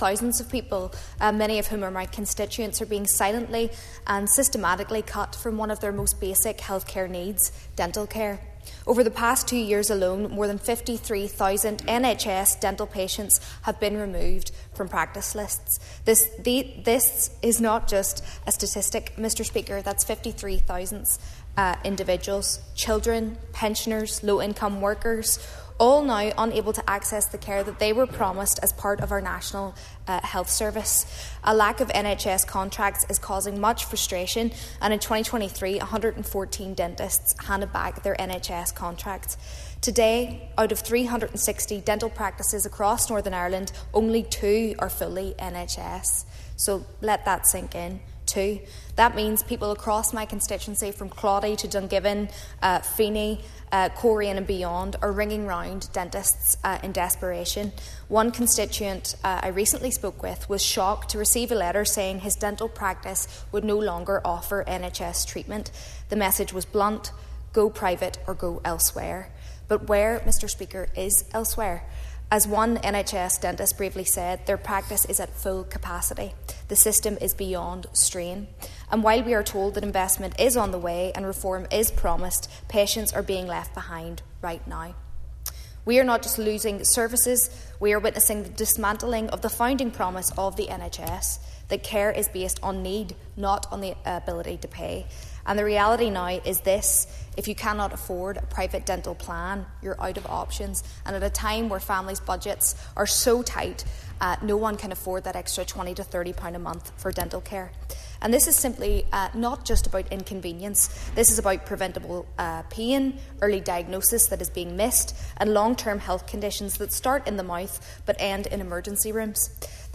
Calling for a root and branch review of how the dental service is structured and funded, Ms Hunter told the Assembly the situation is worsening……………